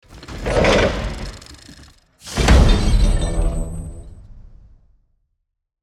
Download Free Sci-Fi Robotic Sound Effects | Gfx Sounds
Heavy-giant-robot-footsteps-single-step.mp3